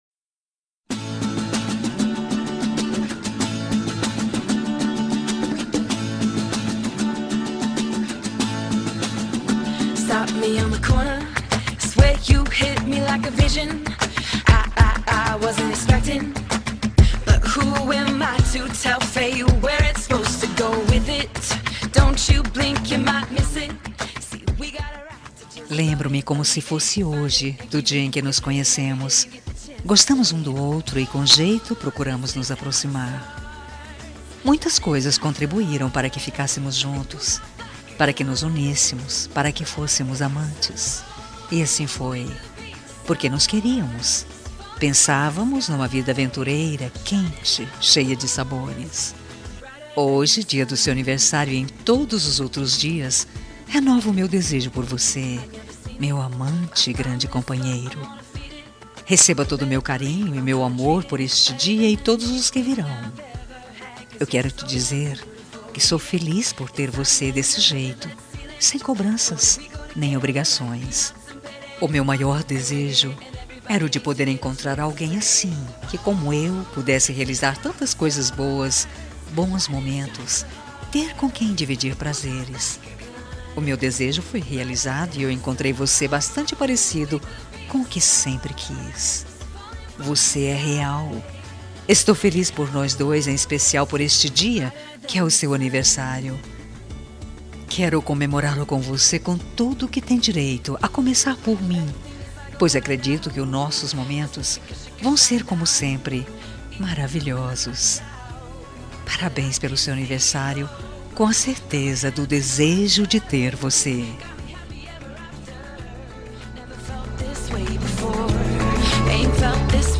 Telemensagem de Aniversário Romântico – Voz Feminina – Cód: 202123 – Amante